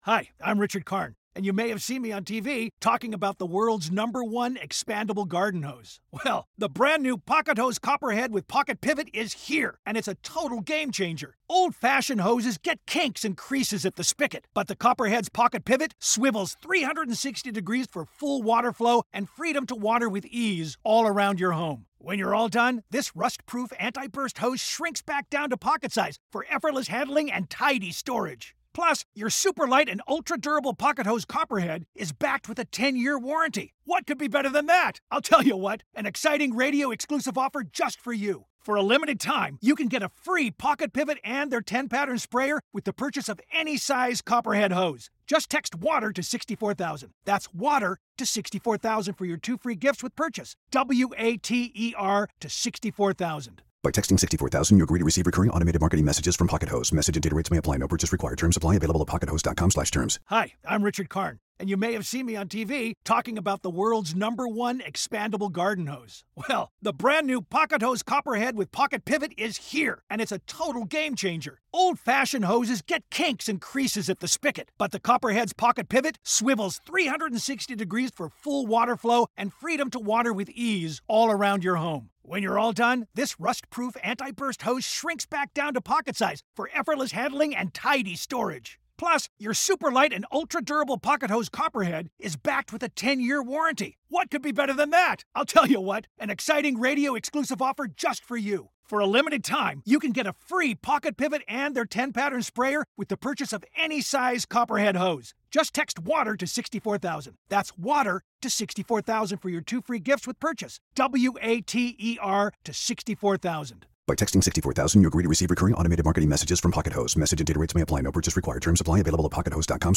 Dragon Age The Veilguard Hands-On Impressions and Dev Interview